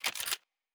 Weapon 07 Reload 3.wav